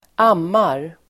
Uttal: [²'am:ar]